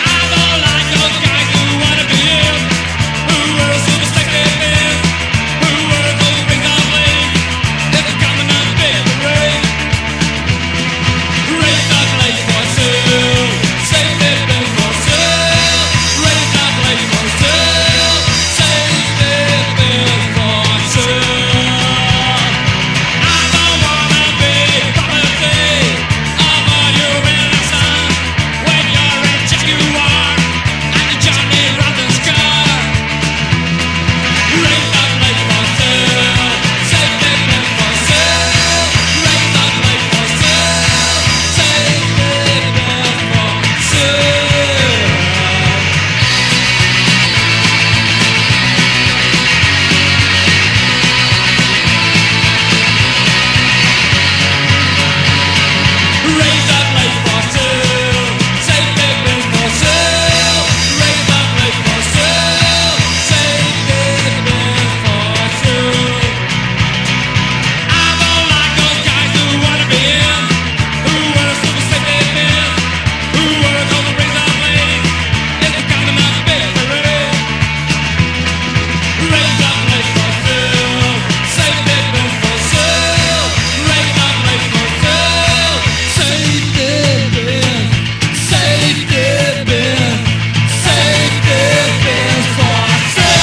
ROCK / 90''S～ / NEO-ACO/GUITAR POP
青春疾走系のキラーなバースト・ポップ